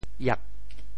烨（燁） 部首拼音 部首 火 总笔划 10 部外笔划 6 普通话 yè 潮州发音 潮州 ieb8 文 iag4 文 潮阳 iab8 澄海 iag8 揭阳 iab8 饶平 iab8 汕头 iab8 中文解释 潮州 ieb8 文 对应普通话: yè 火光，日光，光辉灿烂：～～。